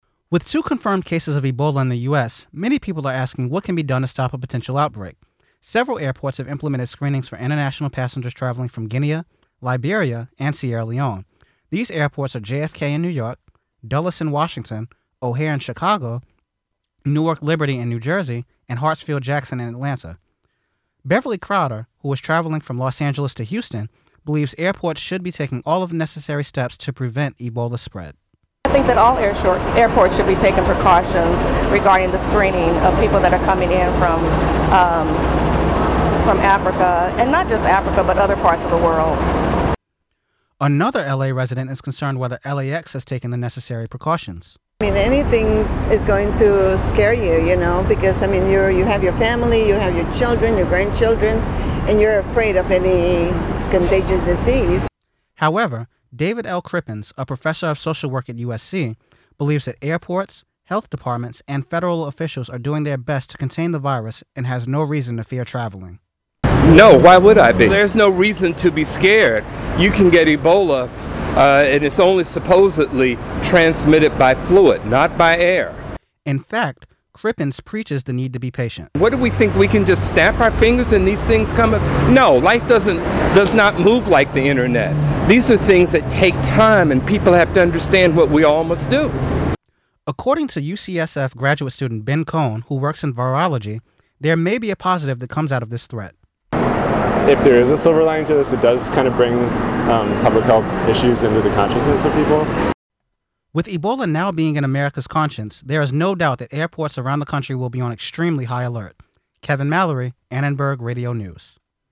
A recent scare at Los Angeles International Airport has some traveleres confused abut the state of the care their airport is taking. Anmnenberg Radio News spoke with travelers at SoCal's busiest airport about whether their Ebola fears were causing any changes in their travel plans.